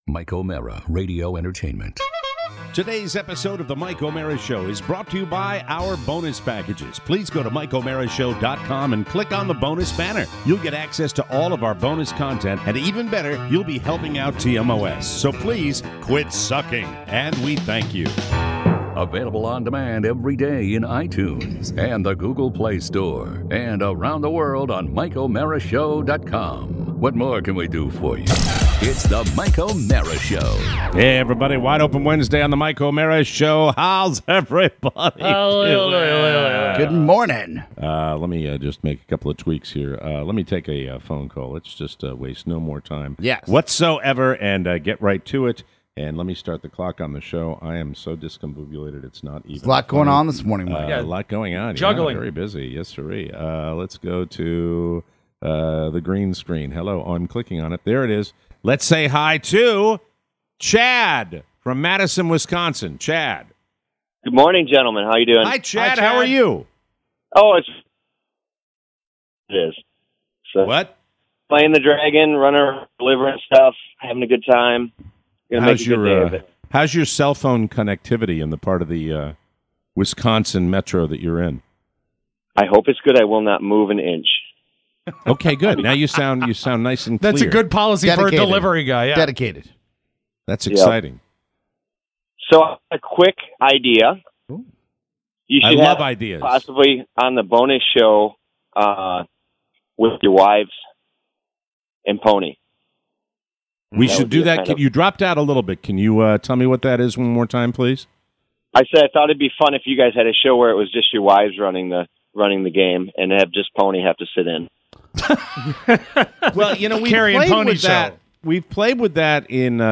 Your marvelous calls!